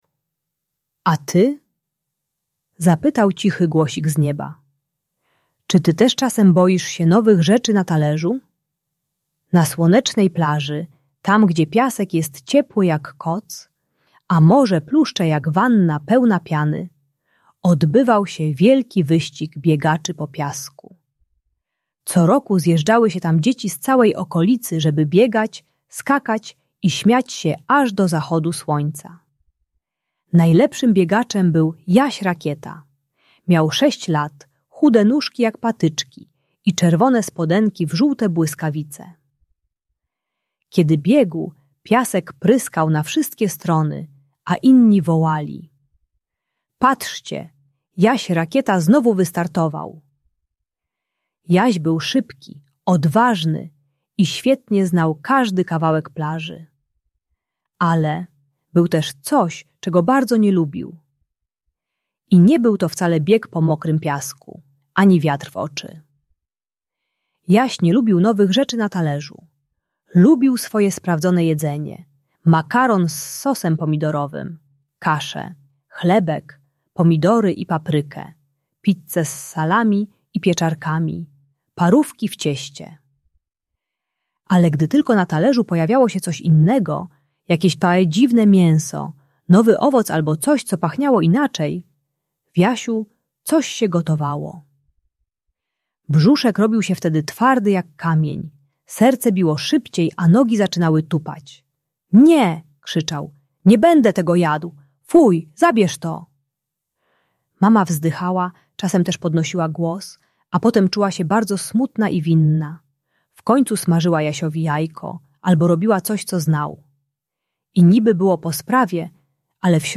Historia Jasia Rakiety - Problemy z jedzeniem | Audiobajka